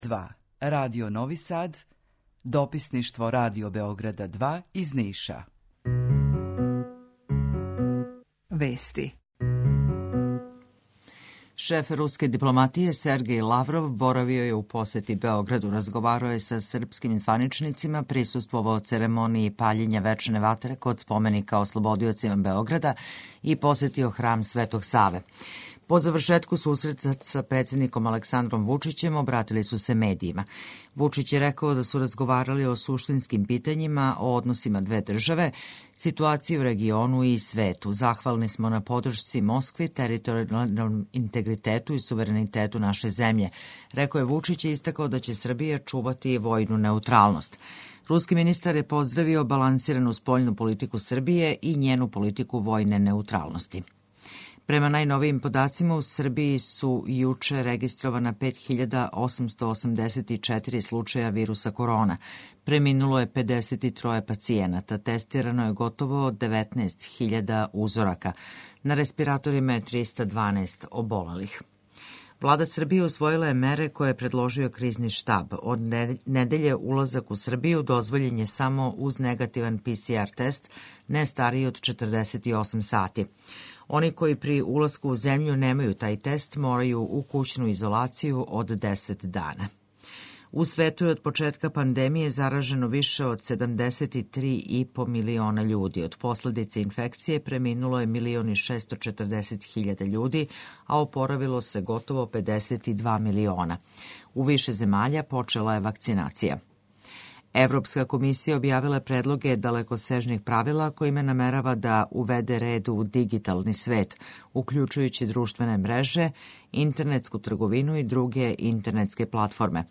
Укључење Грачанице
Јутарњи програм из три студија
У два сата, ту је и добра музика, другачија у односу на остале радио-станице.